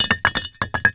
CONSTRUCTION_Bricks_Fast_02_loop_mono.wav